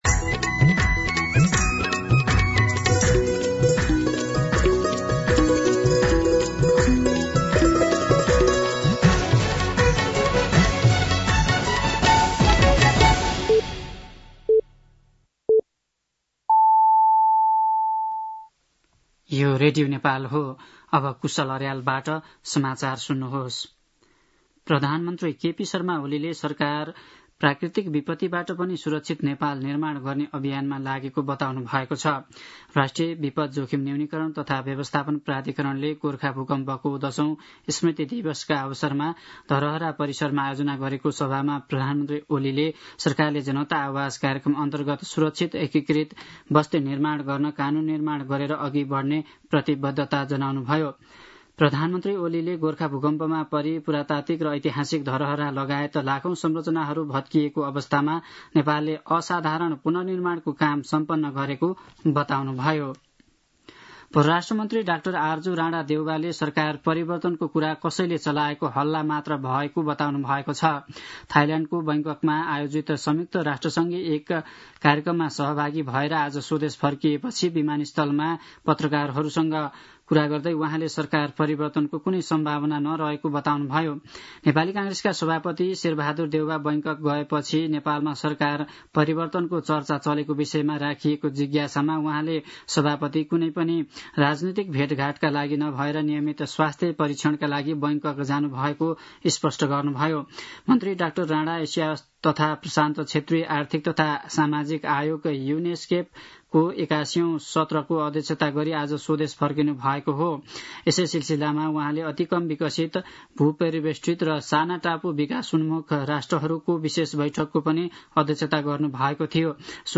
दिउँसो ४ बजेको नेपाली समाचार : १२ वैशाख , २०८२
4-pm-Nepali-News-4.mp3